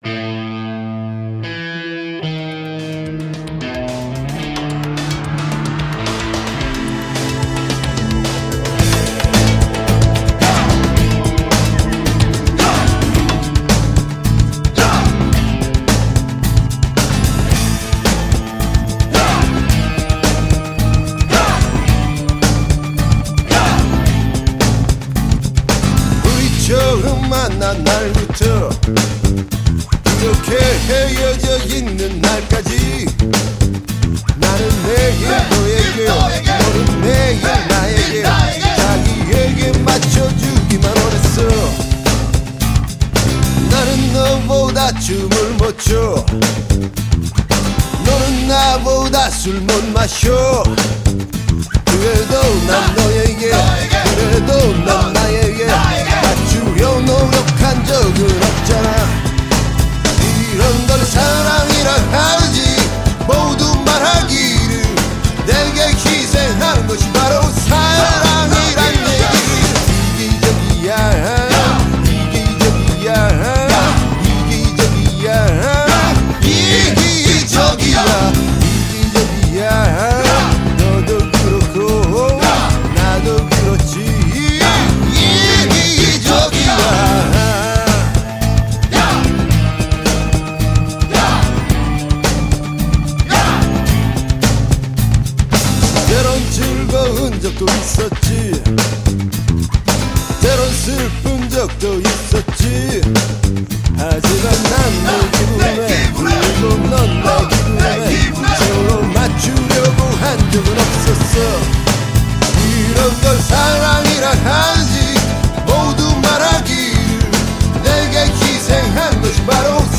보컬, 기타
드럼